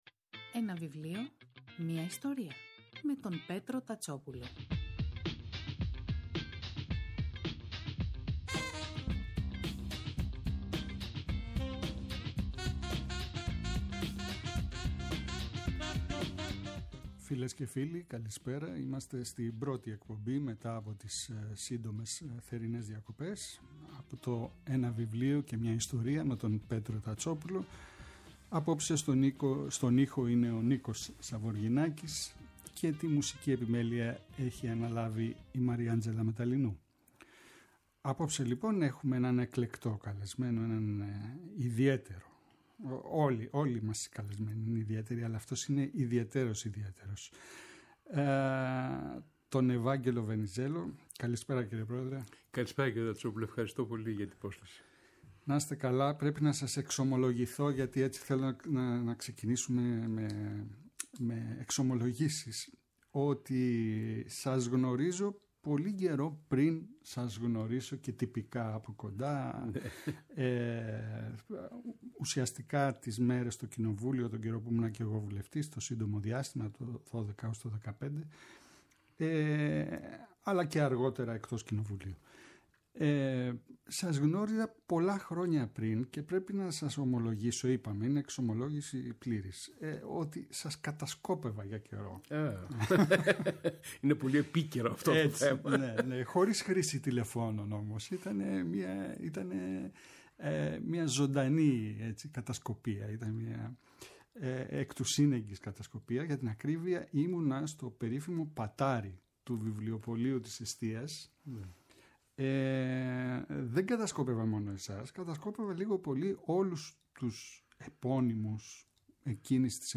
Το Σάββατο 27 Αυγούστου καλεσμένος της εκπομπής ο Ευάγγελος Βενιζέλος για το βιβλίο του “Εκδοχές πολέμου 2009-2022” (εκδόσεις Πατάκη).